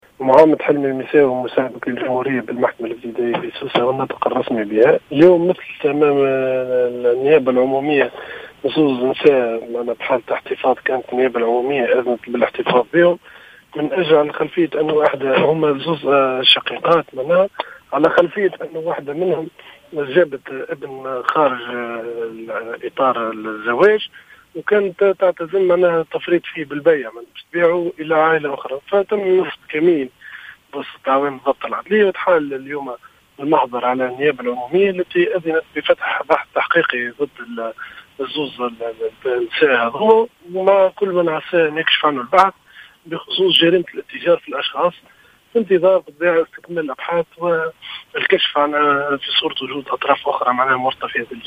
قال مساعد وكيل الجمهورية والناطق الرسمي بالمحكمة الابتدائية بسوسة، محمد حلمي الميساوي في تصريح اليوم لـ"الجوهرة أف أم" إن امرأة مثلت اليوم أمام المحكمة بتهمة الاتجار بالأطفال.